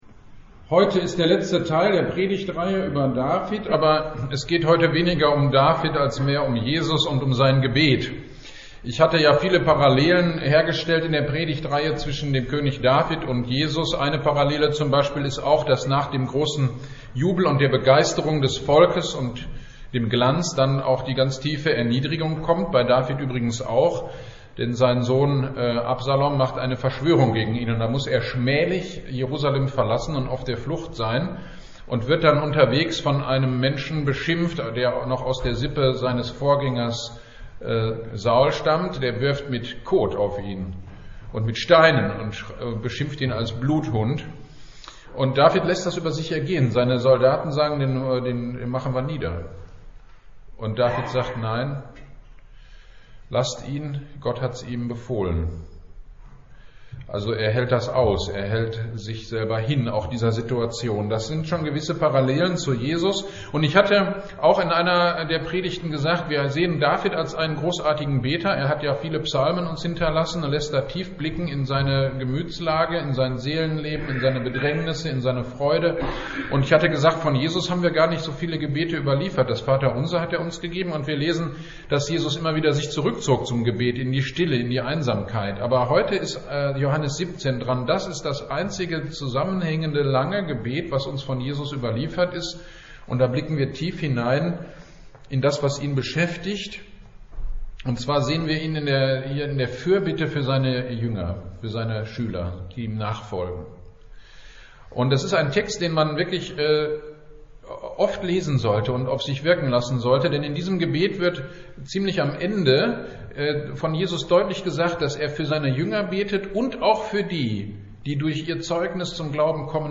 Predigt-zu-Johannes-171-10.mp3